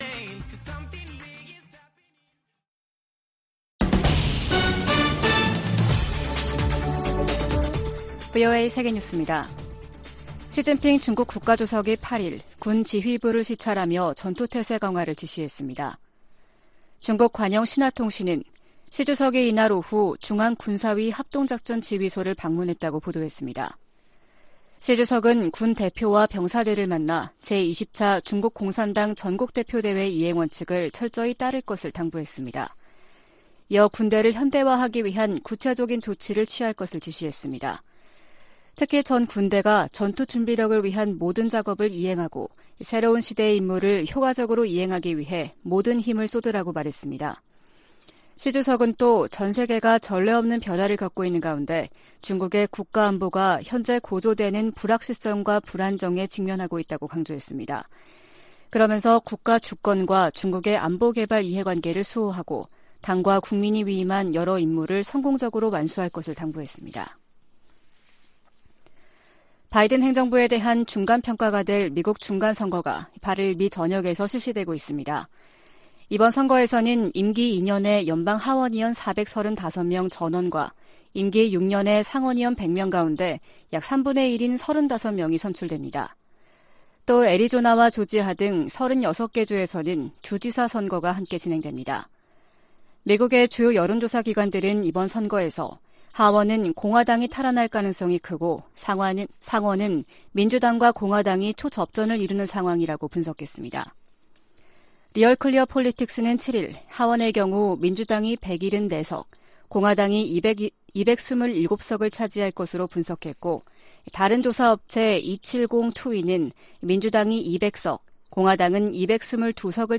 VOA 한국어 아침 뉴스 프로그램 '워싱턴 뉴스 광장' 2022년 11월 9일 방송입니다. 미국에서 임기 2년의 연방 하원의원 435명 전원과 임기 6년의 연방 상원의원 3분의 1을 선출하는 중간선거 투표가 실시되고 있습니다. 미 국무부는 유엔 안보리에서 북한에 대한 제재와 규탄 성명 채택을 막고 있는 중국과 러시아를 정면으로 비판했습니다. 유럽연합은 북한의 잇단 미사일 도발이 전 세계에 심각한 위협이라며 국제사회의 단합된 대응을 촉구했습니다.